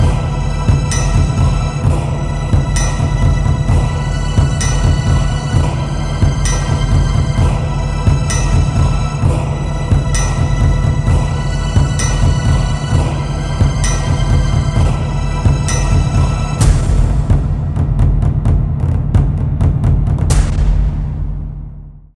Battle Drums. Instrumental Music.